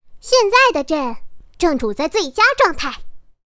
袁世凯行走2.mp3